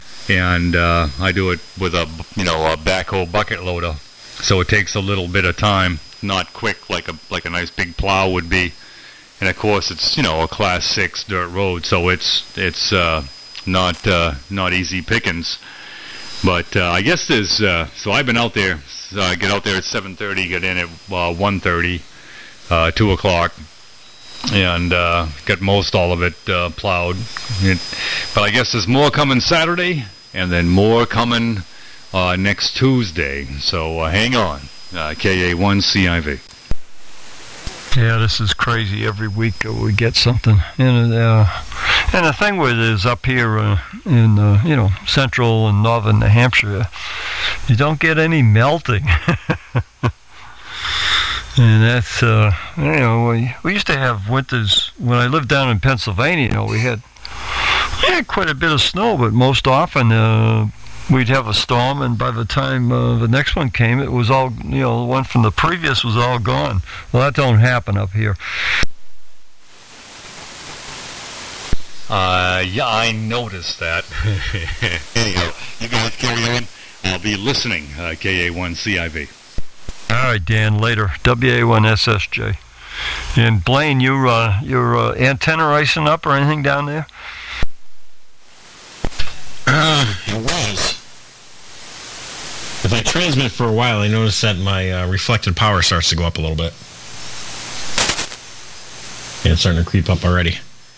The 75 Meter AM Audio Files